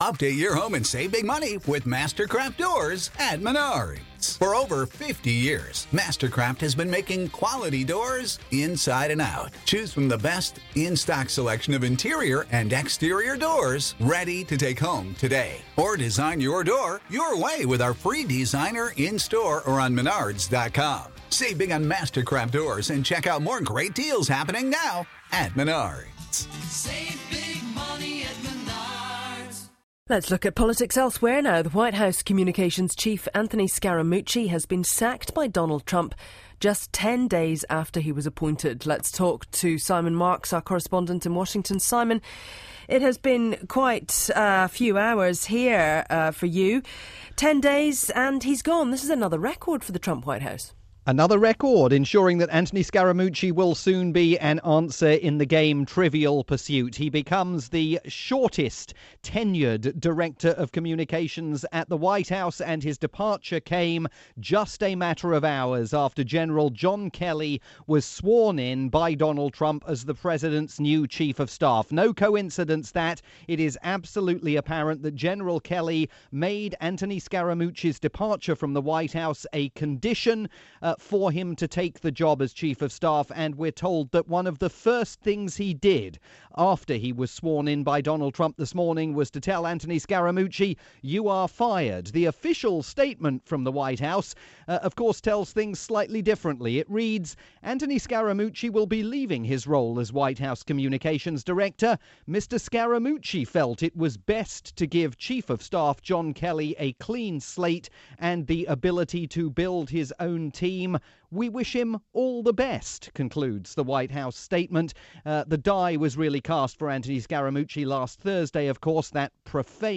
report via Radio New Zealand's "Morning Report" .